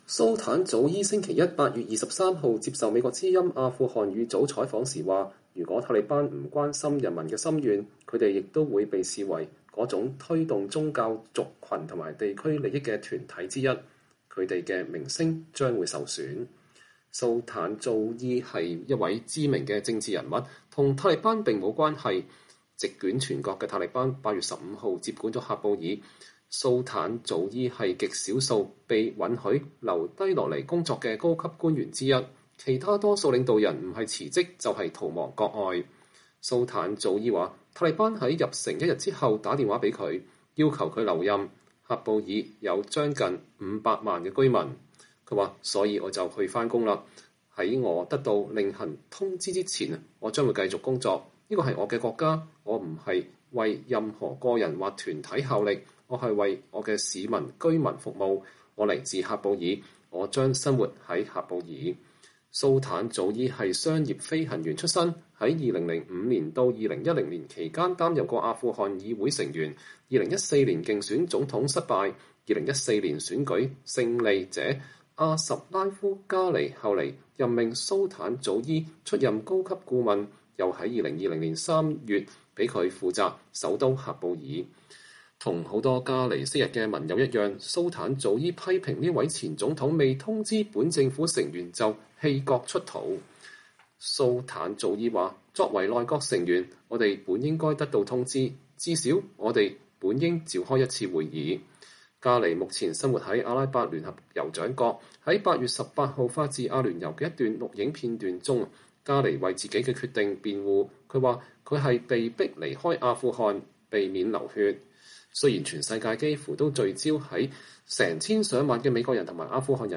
留守崗位的喀佈爾市長接受VOA採訪：“我為我市居民服務”